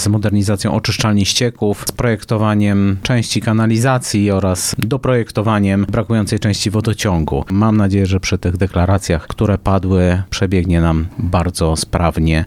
Z czym związany jest projekt mówi Burmistrz Wyśmierzyc Wojciech Sępioł: